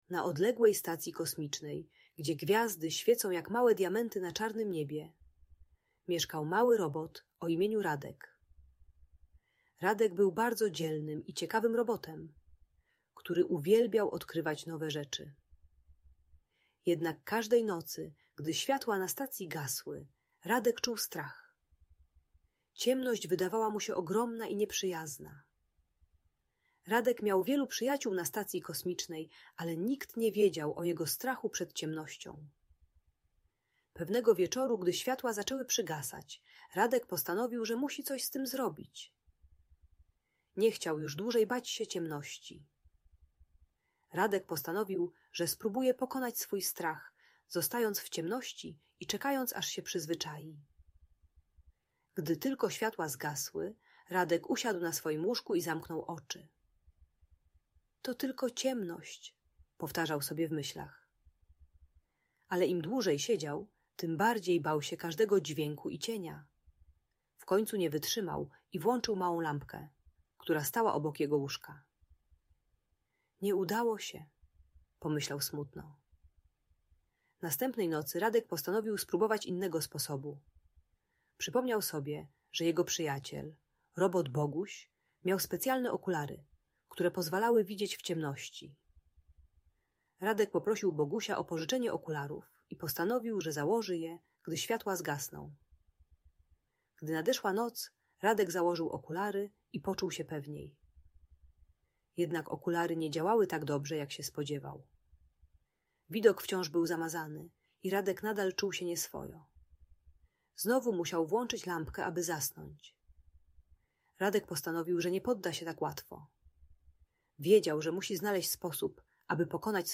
Historia o Radku: Odwaga w ciemności - Audiobajka dla dzieci